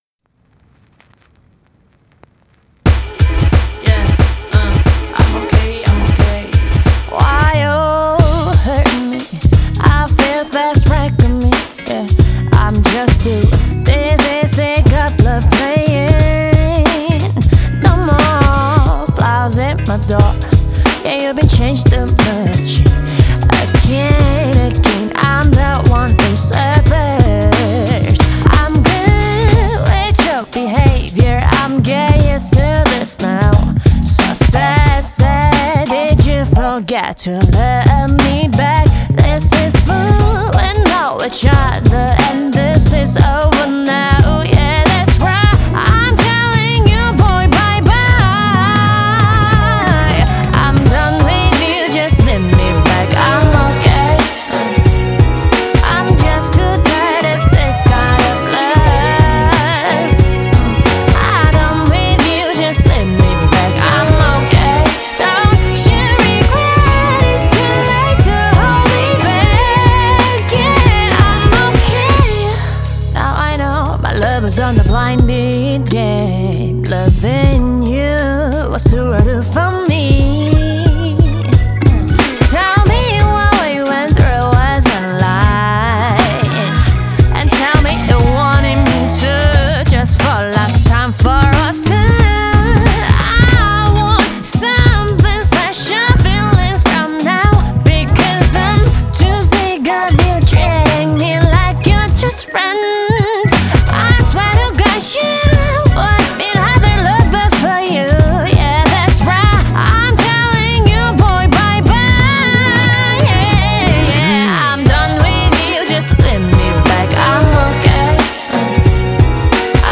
ی خواننده- ترانه سرا R&B عه